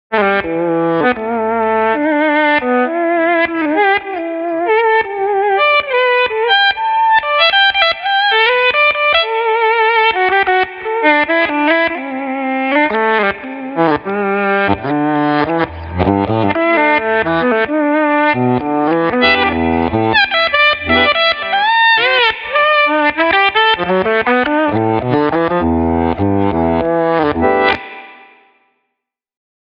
Tältä Flashback X4:n efektityypit kuulostavat:
reverse.mp3